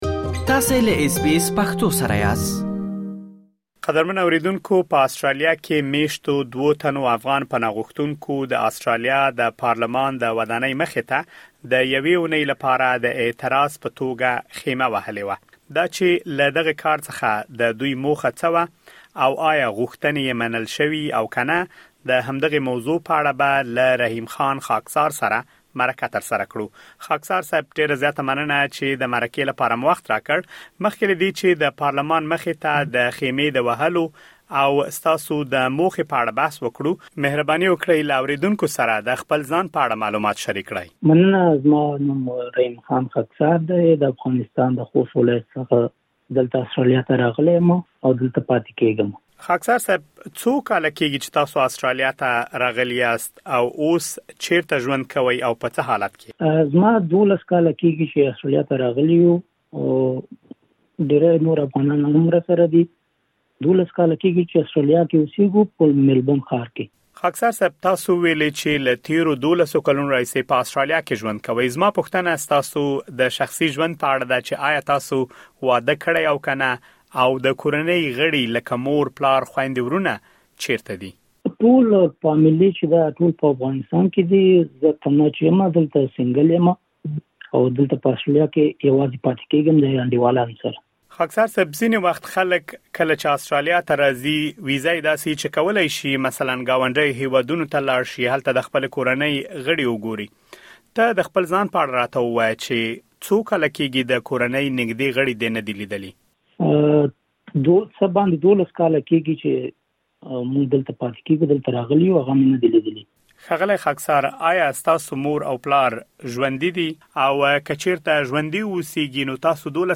لا ډېر معلومات په مرکې کې اورېدلی شئ.